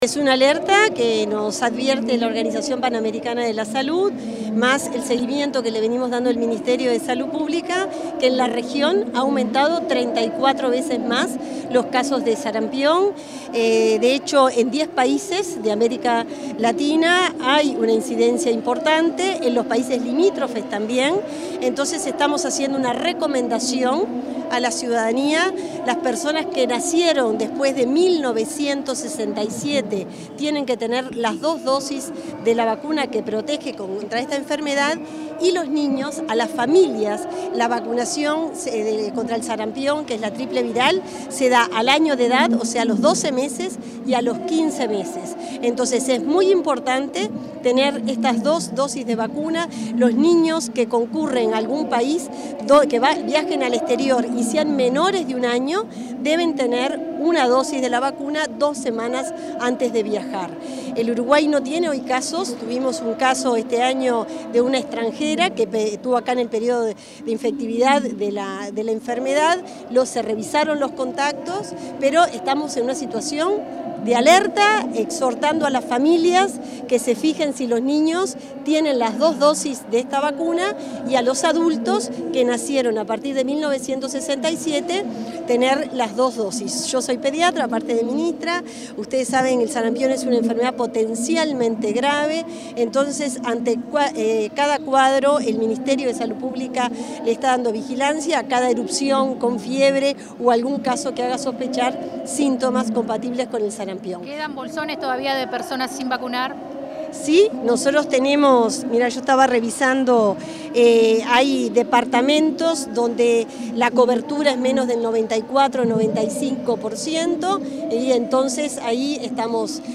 Declaraciones de la ministra de Salud Pública, Cristina Lustemberg